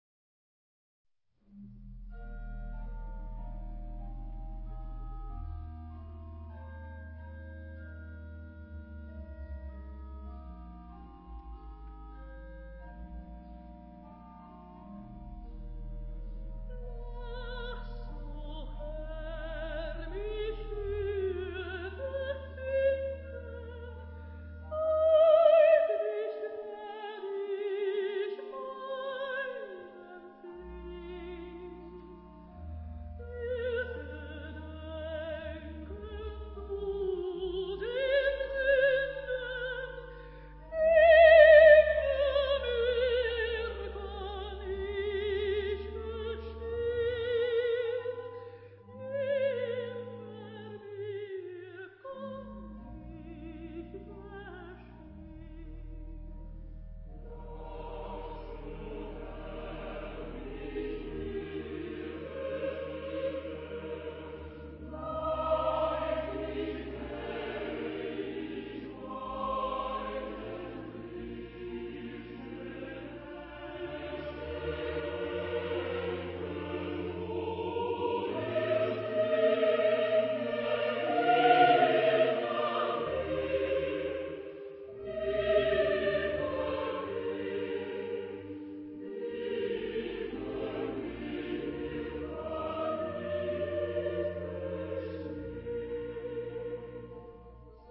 Genre-Style-Form: Hymn (sacred) ; Sacred
Type of Choir: SATB  (4 mixed voices )
Soloist(s): Alto (1)  (1 soloist(s))
Instrumentation: Orchestra  (18 instrumental part(s))
Instruments: Flute (2) ; Oboe (2) ; Clarinet (2) ; Bassoon (2) ; Horn (2) ; Trombone (2) ; Timpani (2) ; Violin I ; Violin II ; Viola (1) ; Cello (1) ; Double bass (1)
Tonality: B flat major ; E flat major
sung by Kammerchor Stuttgart conducted by Frieder Bernius